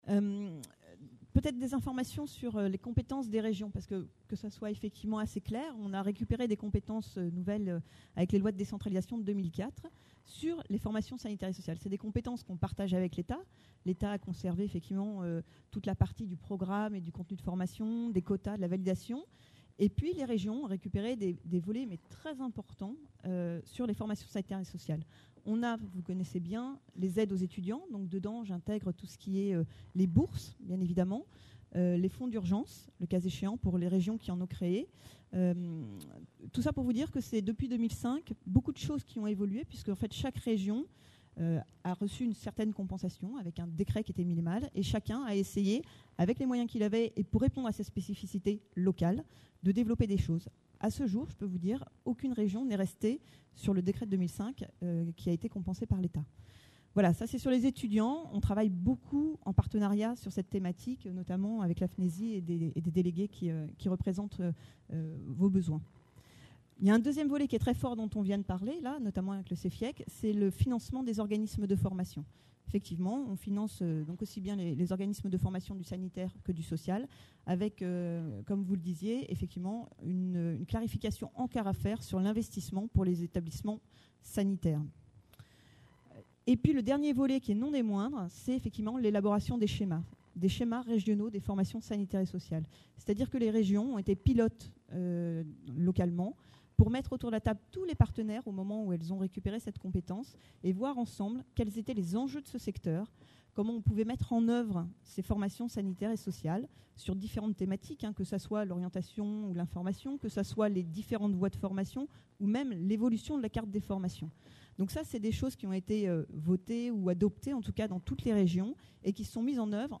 Conférence enregistrée lors 10ème Congrès National des Etudiants en Soins Infirmiers (FNESI) – Paris le 26 novembre 2010 - L’évolution générale de l’offre de soins.